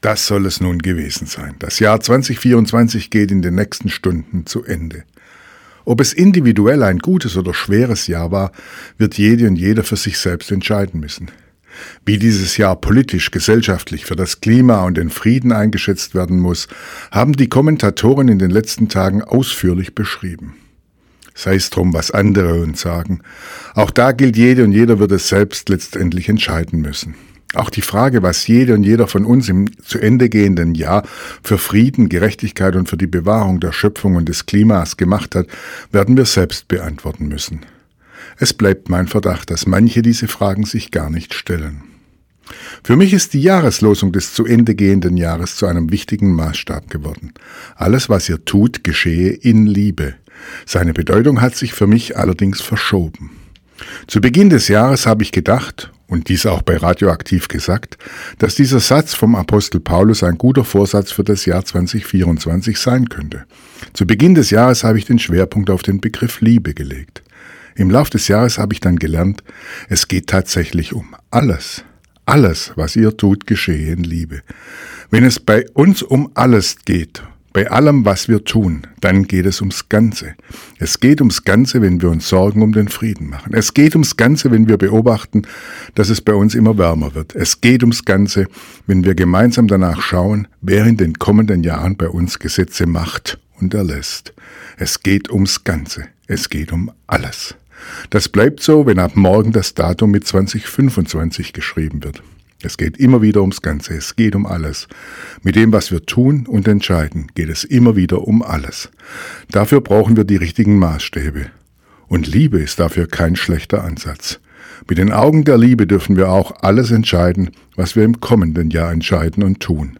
Radioandacht vom 31. Dezember